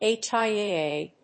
/ˈeˈtʃaɪˈeˈe(米国英語), ˈeɪˈtʃaɪˈeɪˈeɪ(英国英語)/